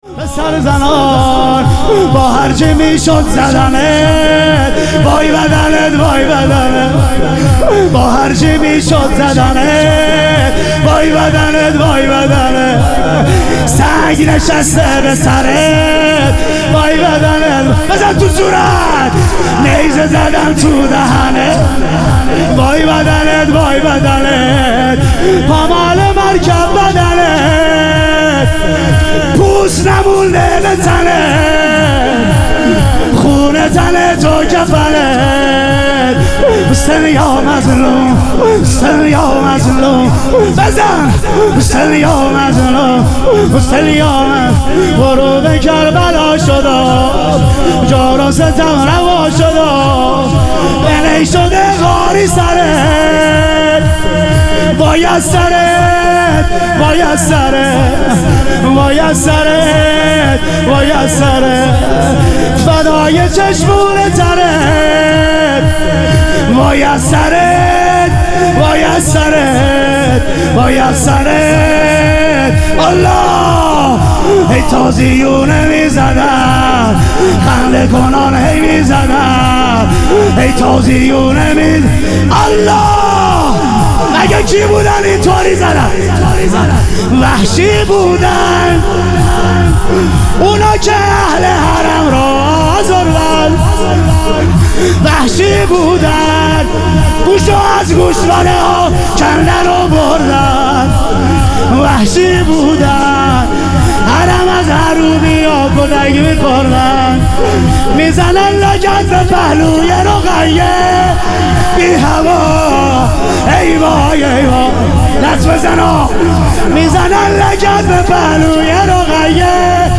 محرم الحرام - شور